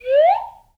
whistle_slide_up_07.wav